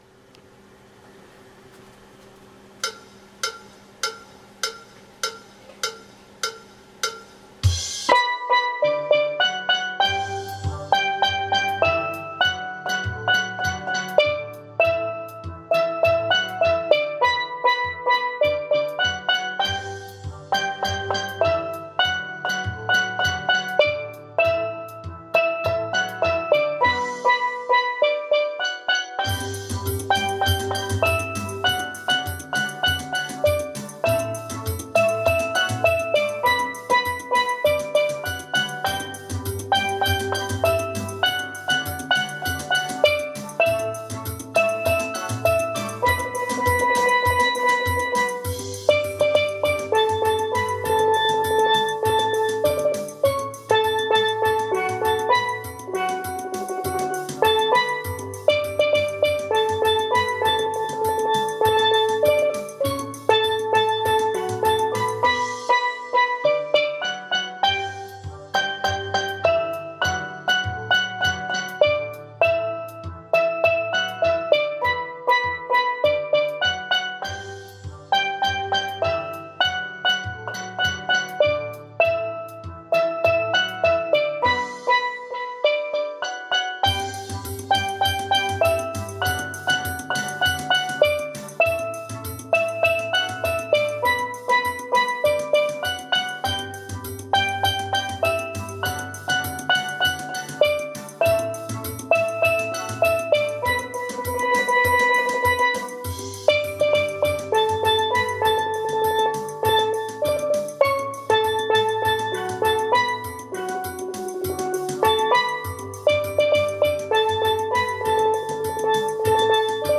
Kaducia Steel Band
demi refrain et fin ralentie.
Ténor Magic Vid .mp3